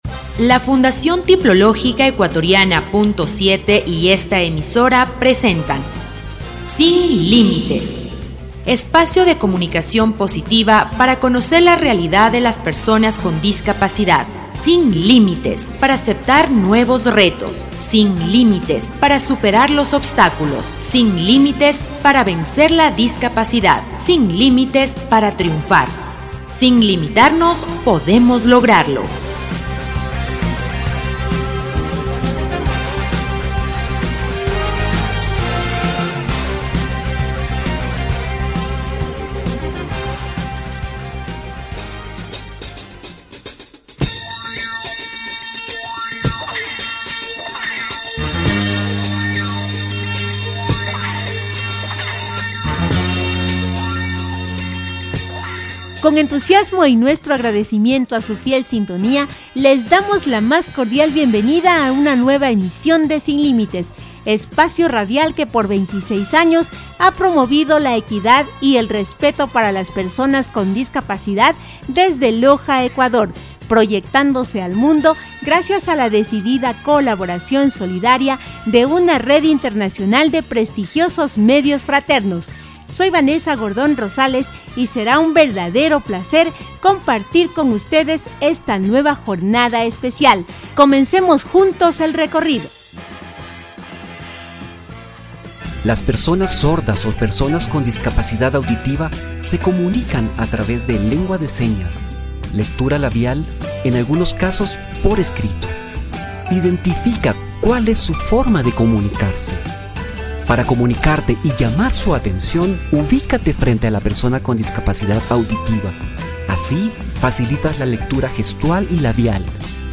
Programa radial «Sin Límites» 1340